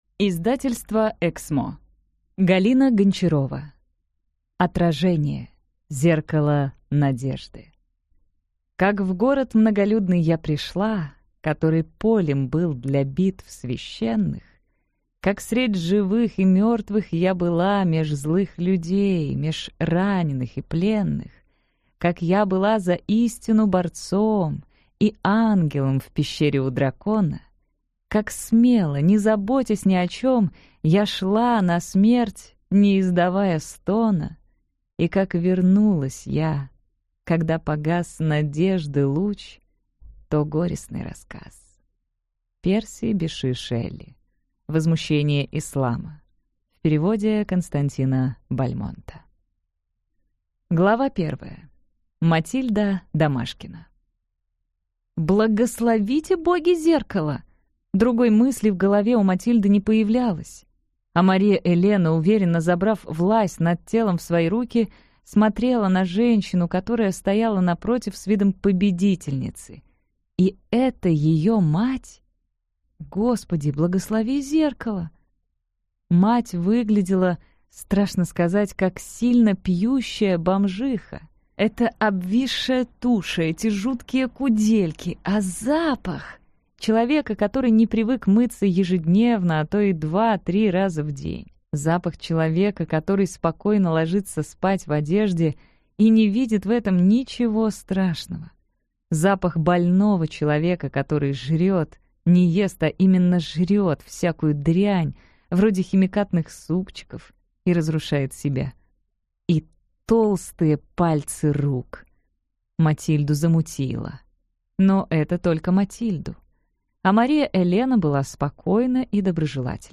Аудиокнига Отражение. Зеркало надежды - купить, скачать и слушать онлайн | КнигоПоиск